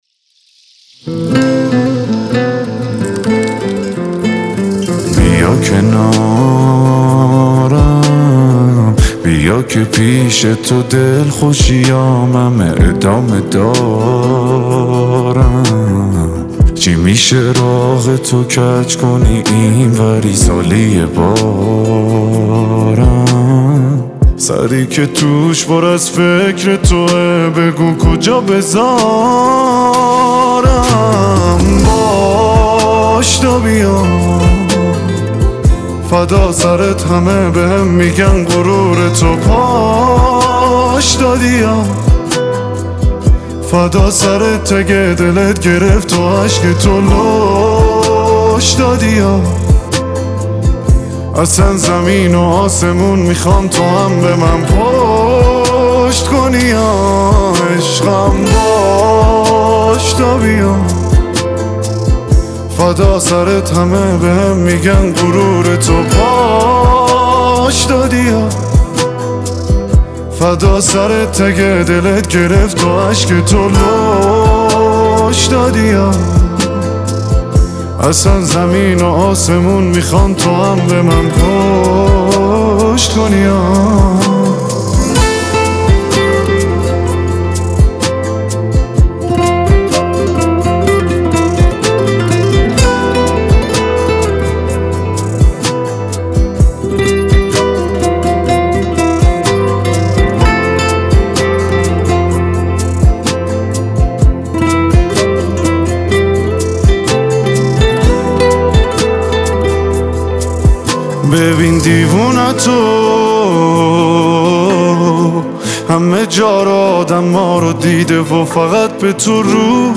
غمگین
عاشقانه و غمگین